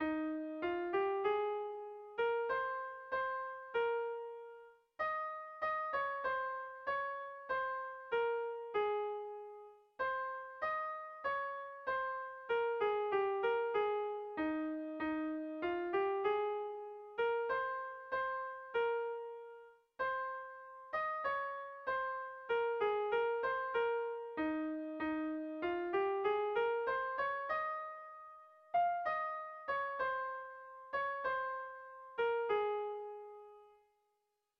AB1B2